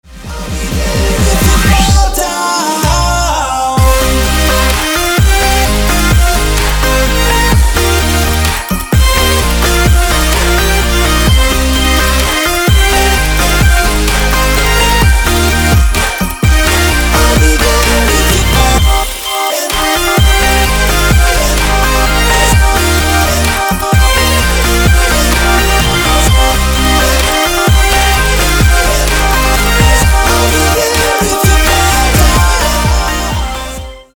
• Качество: 256, Stereo
Electronic
EDM
club